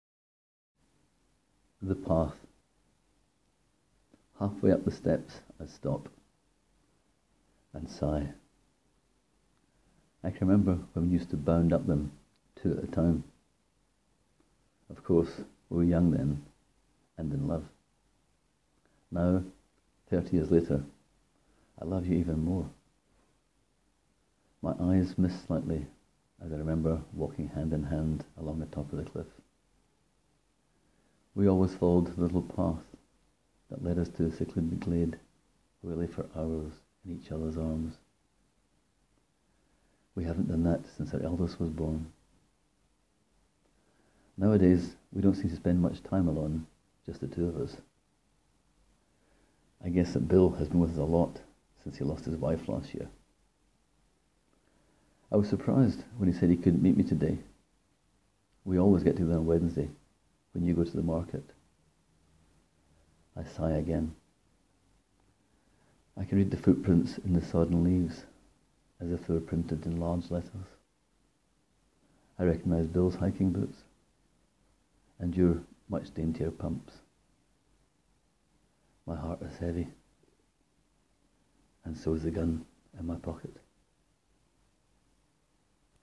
Click here to hear me read my story:
Still have an accent, I see.